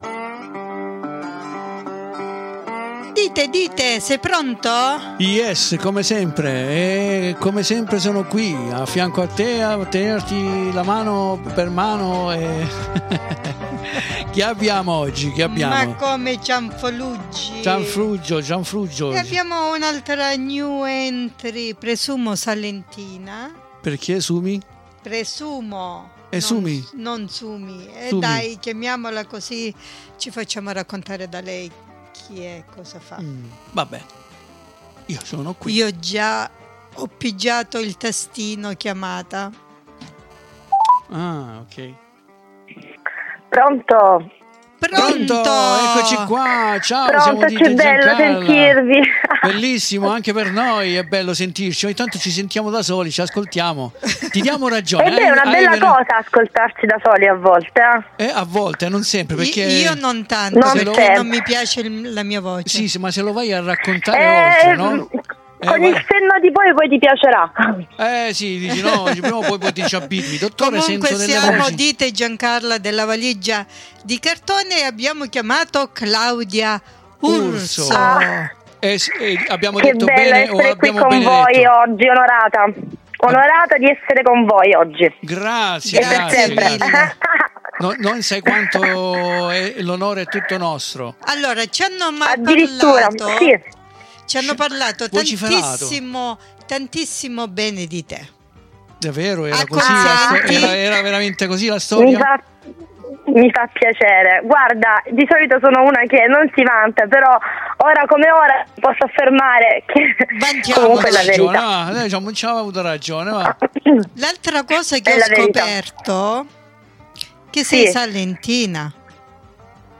SI, PROPRIO COSÍ HA UN'ENERGIA CHE STRABORDA DA TUTTE LE PARTI, A TUTTO TONDO!